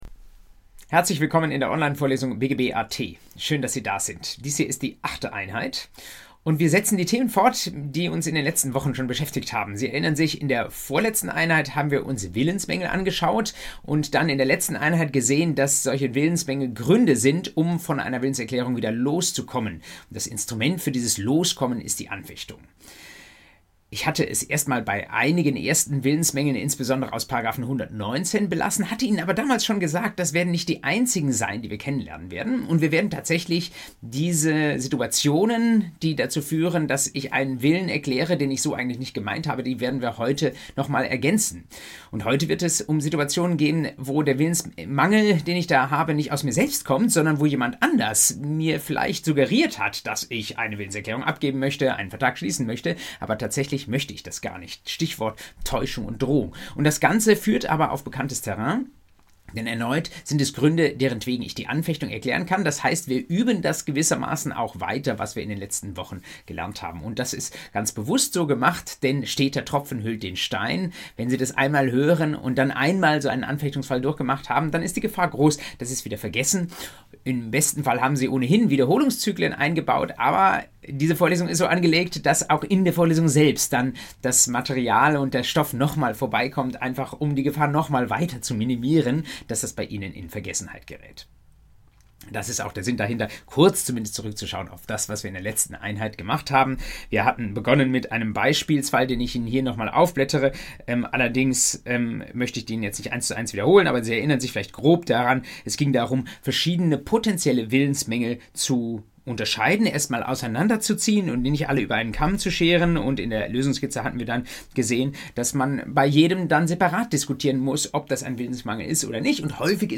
BGB AT Folge 8: Täuschung und Drohung ~ Vorlesung BGB AT Podcast